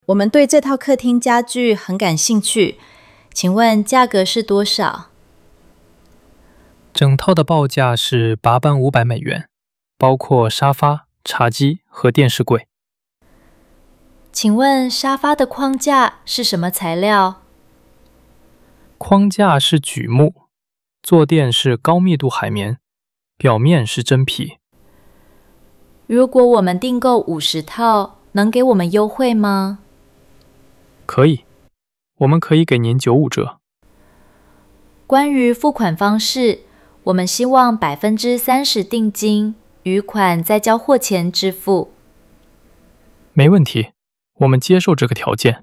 Hội thoại 2: Đàm phán hợp đồng nội thất (hỏi giá, thông số kỹ thuật, thương lượng thanh toán)